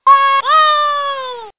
One of Toad's voice clips in Mario Kart: Super Circuit